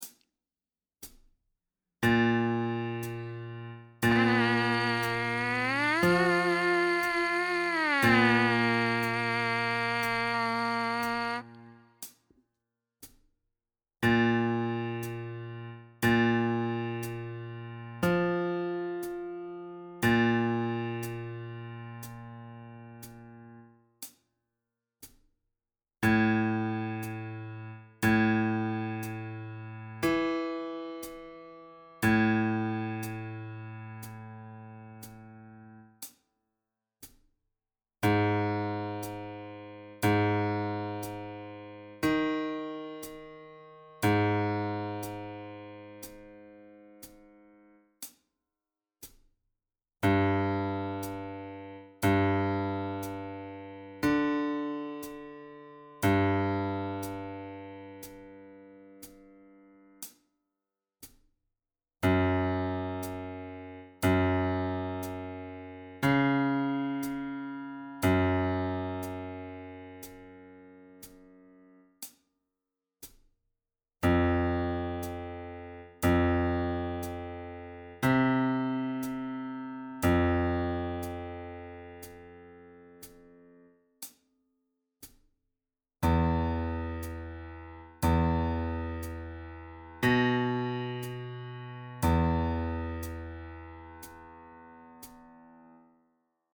Mouthpiece Buzzing #2 play along